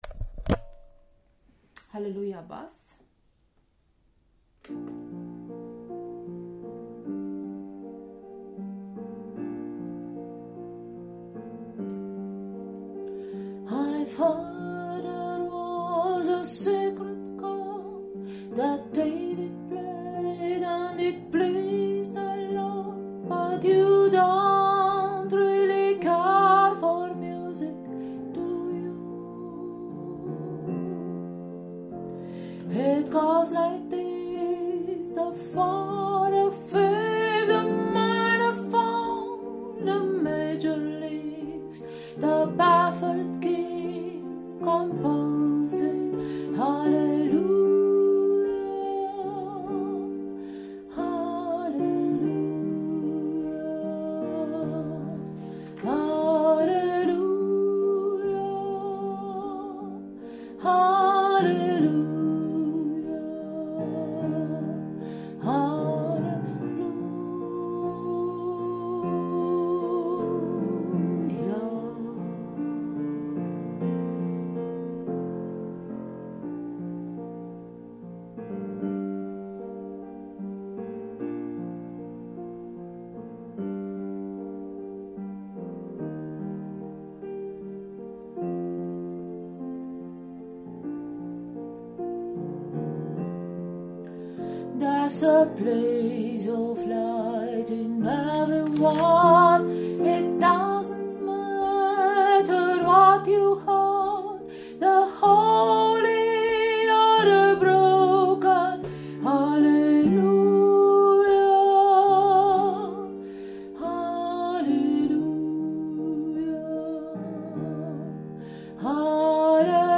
Hallelujah – Bass
HallelujahCohen-Bass.mp3